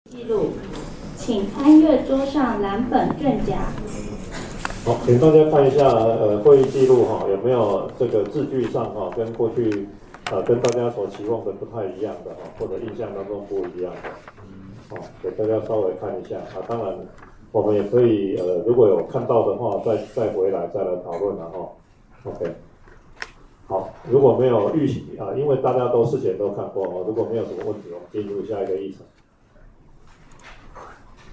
會議實錄錄音檔-113年5月